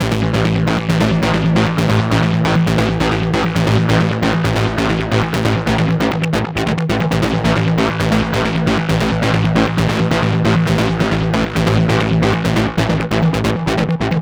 Session 04 - Distorted Bass.wav